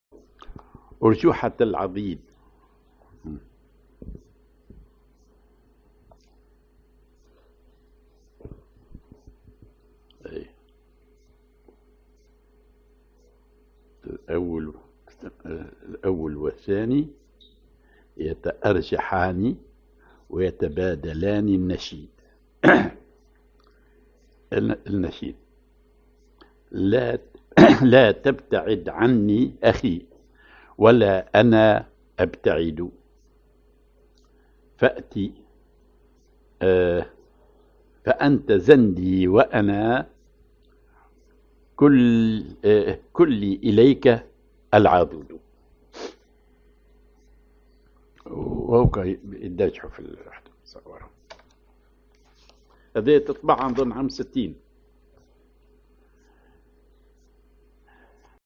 Maqam ar العراق تونسي
genre نشيد